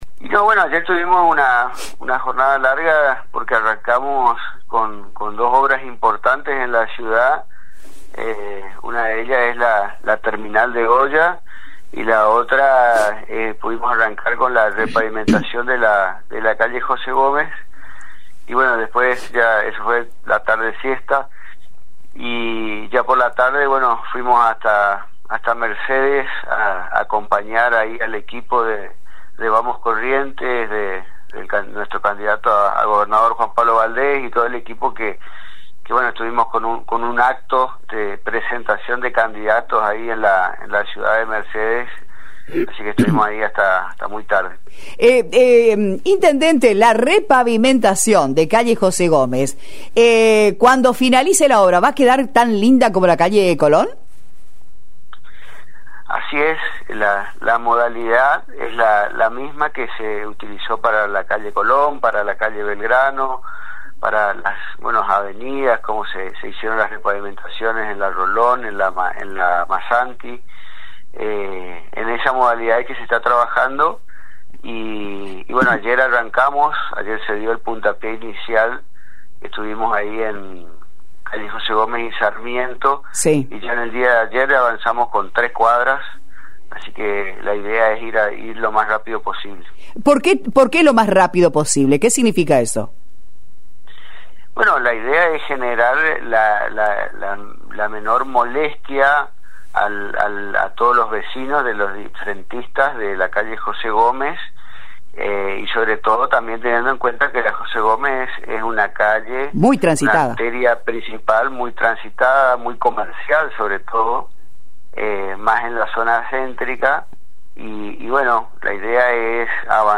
(AUDIO DE LA ENTREVISTA):
En comunicación telefónica al programa «Mañanitas Correntinas», Mariano Hormaechea hizo una reseña de las actividades y los desafíos planteados desde la asunción al frente del Municipio de la ciudad, los ejes fundamentales de su administración.